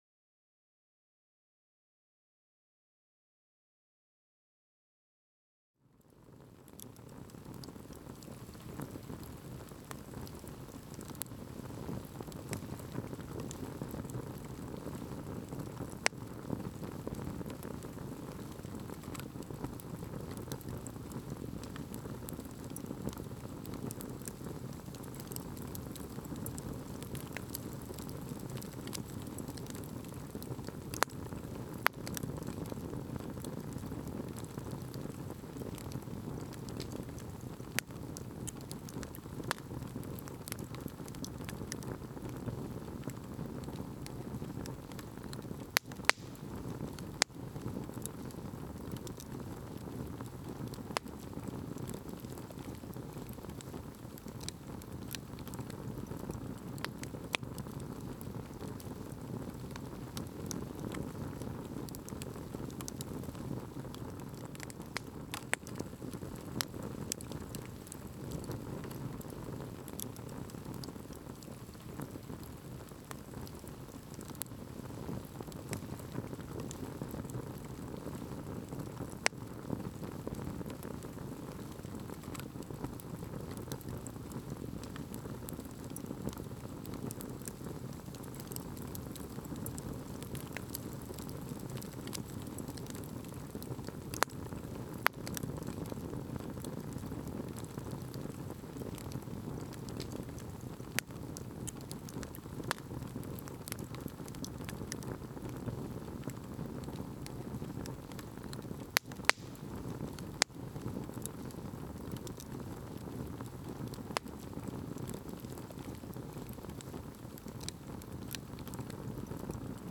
SFX_FireSmall.ogg